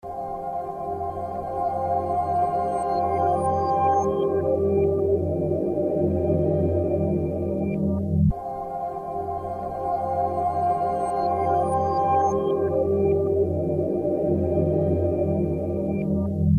BPM 116